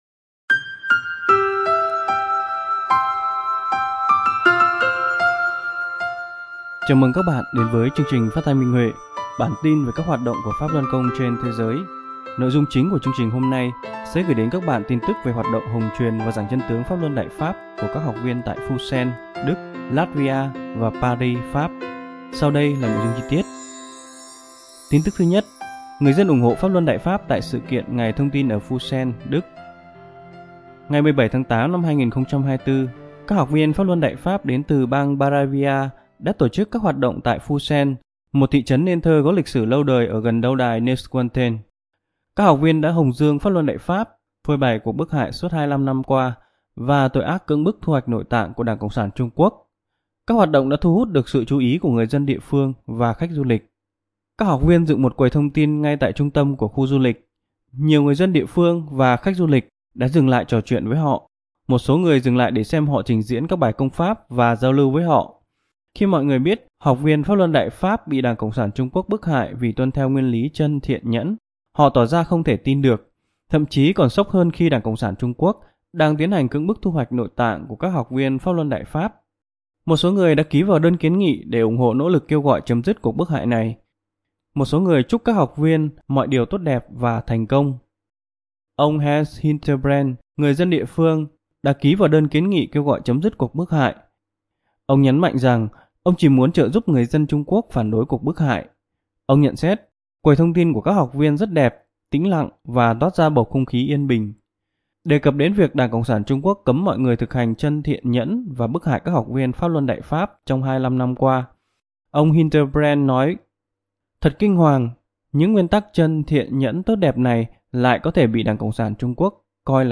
Chương trình phát thanh số 214: Tin tức Pháp Luân Đại Pháp trên thế giới – Ngày 2/9/2024